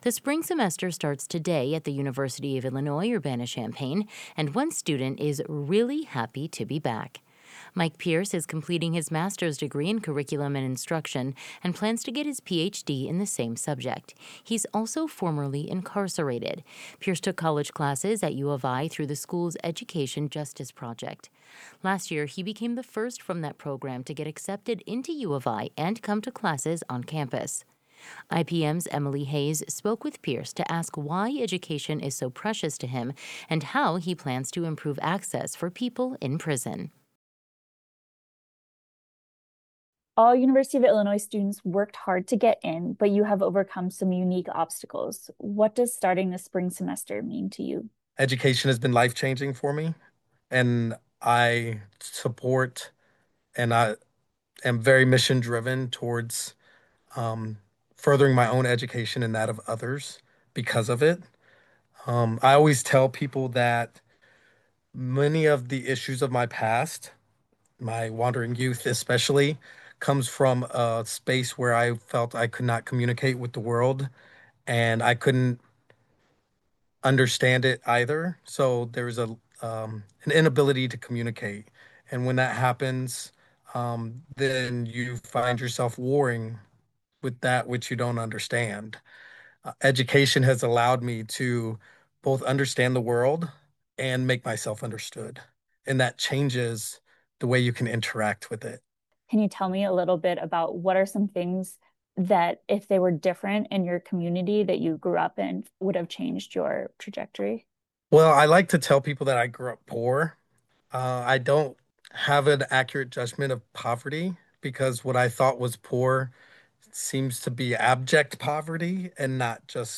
This interview has been shortened and lightly edited for clarity.